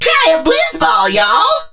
lou_start_vo_02.ogg